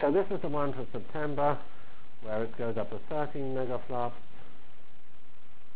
From Feb 5 Delivered Lecture for Course CPS616 -- Java as a Computional Science and Engineering Programming Language CPS616 spring 1997 -- Feb 5 1997.